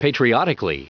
Prononciation du mot patriotically en anglais (fichier audio)
Prononciation du mot : patriotically